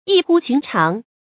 注音：ㄧˋ ㄏㄨ ㄒㄩㄣˊ ㄔㄤˊ
讀音讀法：
異乎尋常的讀法